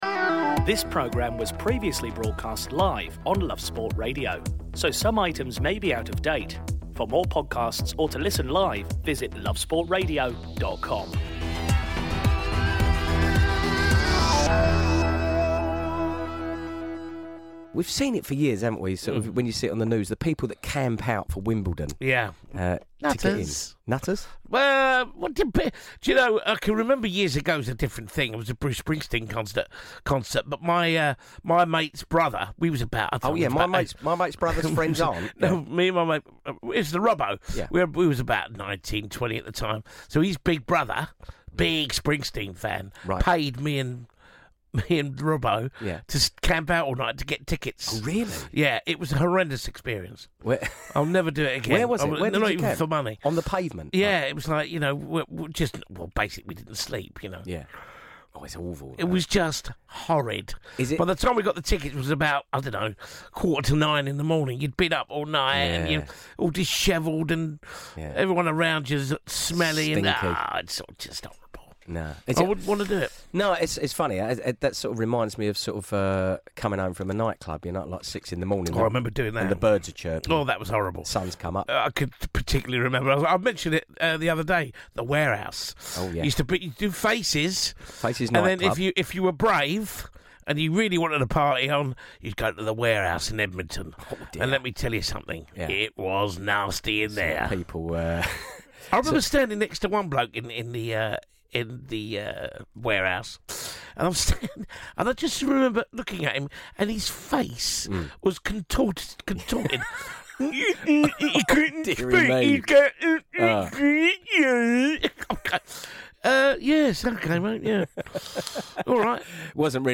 The boys speak to a Happiness Coach to see what they can do to give the nation a boost during these trying times in which we live!